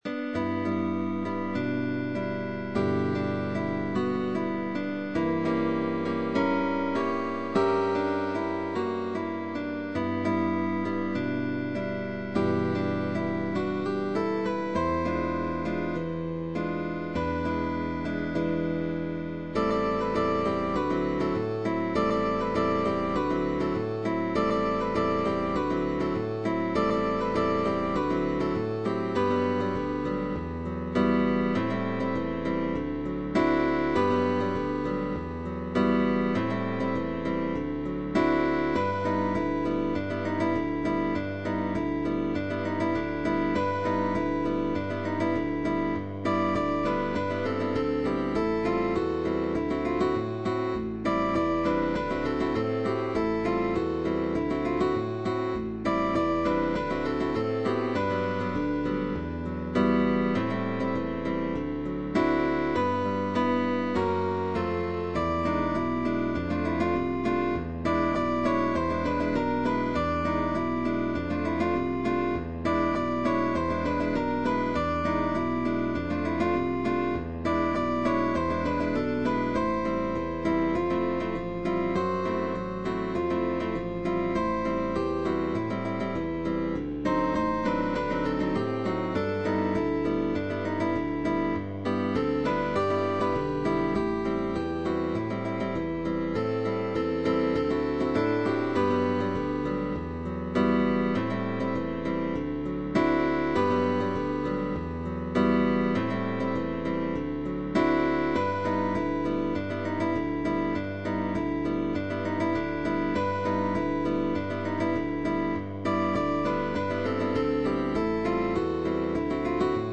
GUITAR TRIO
Change of position, slurs, chords, barres,...